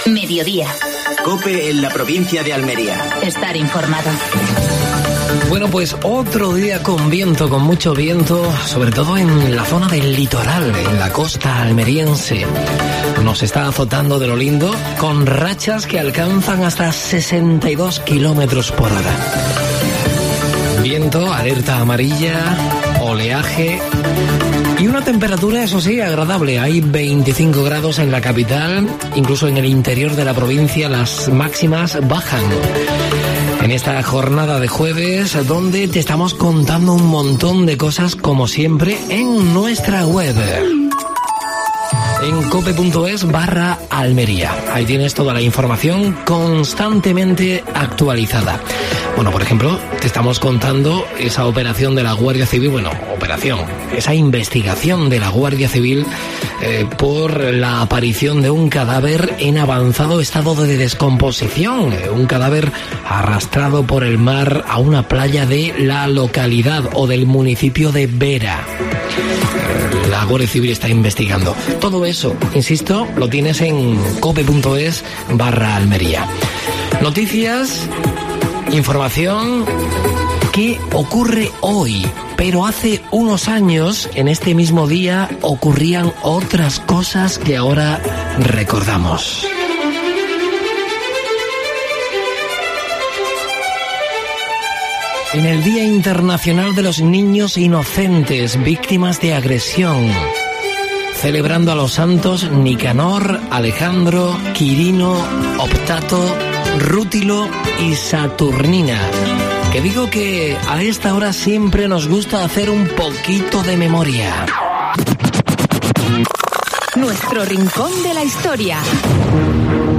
AUDIO: Actualidad en Almería. Entrevista a Javier Aureliano García (presidente de la Diputación Provincial de Almería).